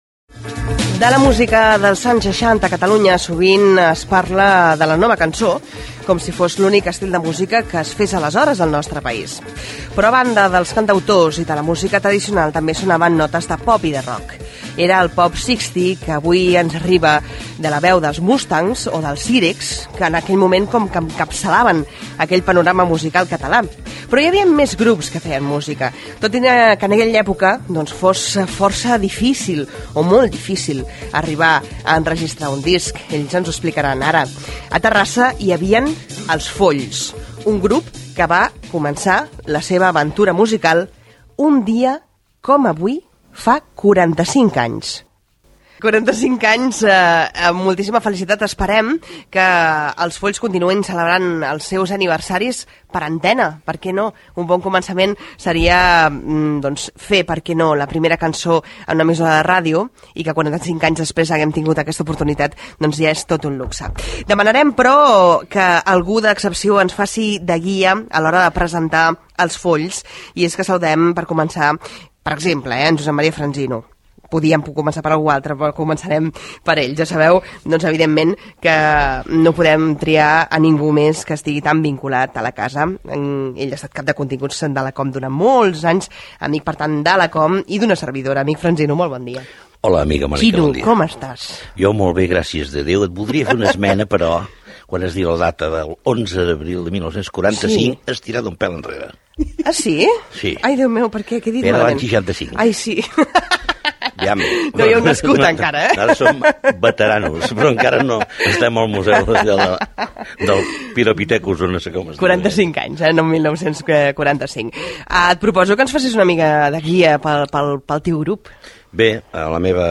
Eureka: entrevista Els Folls - COM Ràdio, 2010